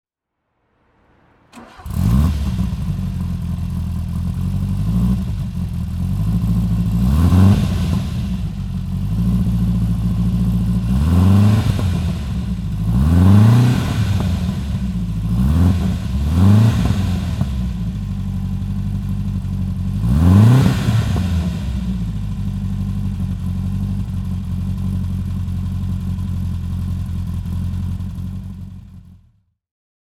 Renault 5 Turbo (1982) - Starten und Leerlauf